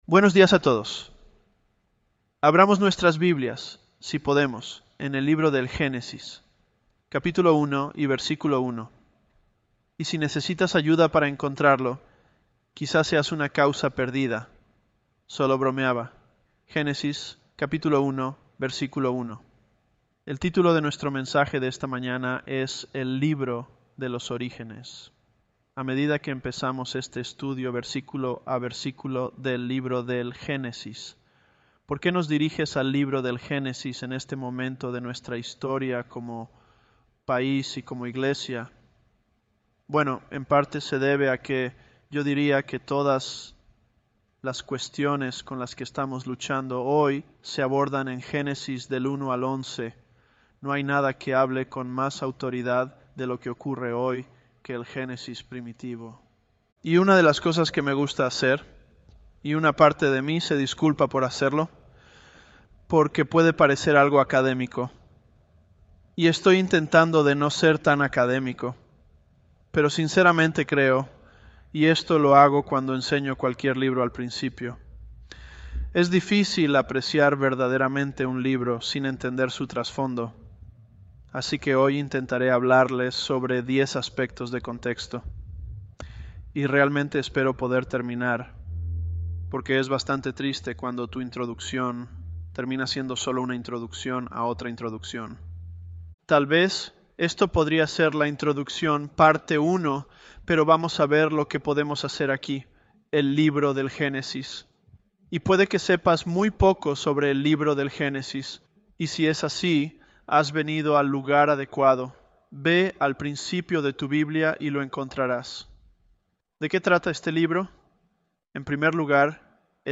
Sermons
ElevenLabs_Genesis-Spanish001.mp3